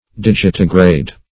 Digitigrade \Dig"i*ti*grade\, n. (Zool.)
digitigrade.mp3